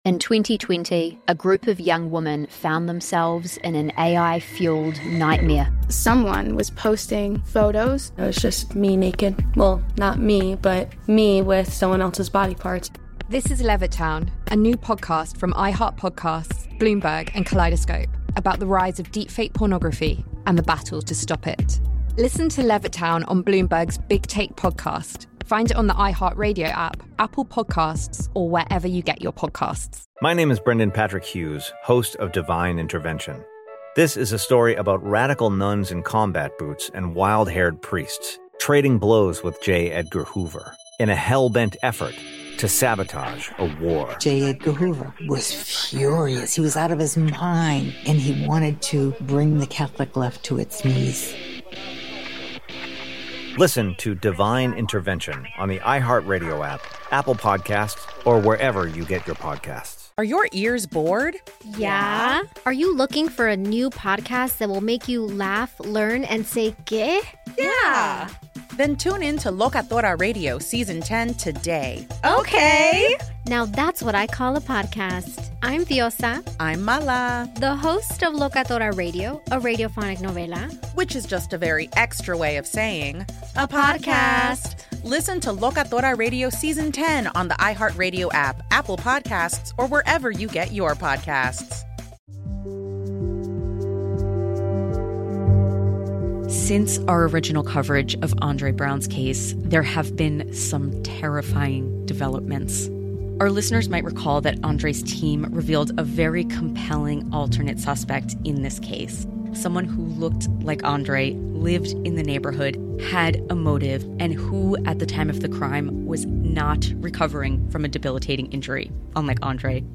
This special edition of Wrongful Conviction was recorded on December 1, 2023 live from the United Justice Coalition (UJC) Summit in New York City. This annual gathering brings together activists from all over the world with the expressed purpose of raising awareness of and devising ways to address some of the key social issues of our time.